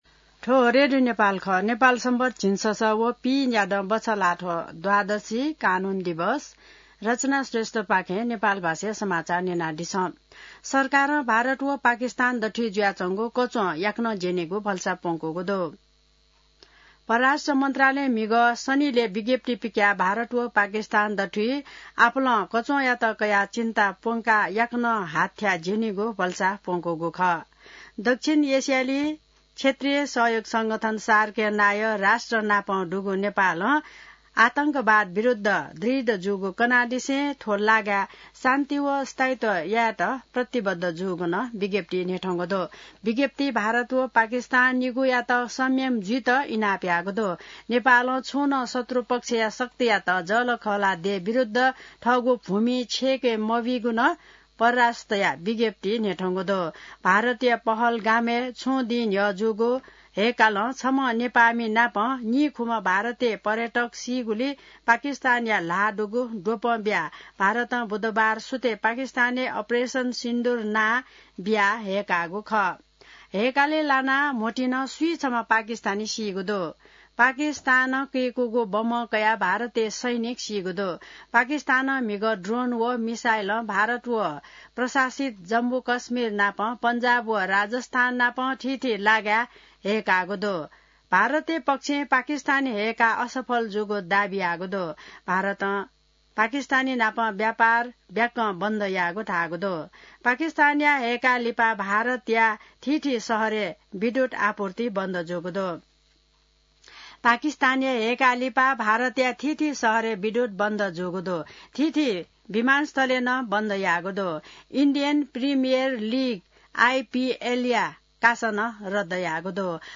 An online outlet of Nepal's national radio broadcaster
नेपाल भाषामा समाचार : २६ वैशाख , २०८२